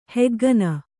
♪ heggana